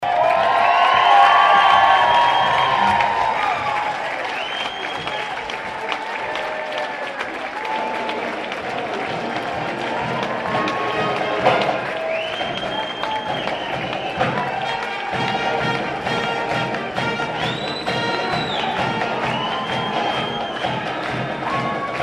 The veterans capped off the trip receiving a warm welcome at Wamego High School Thursday night.
Wamego-Nat-Sound.mp3